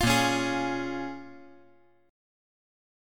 A#m7 Chord
Listen to A#m7 strummed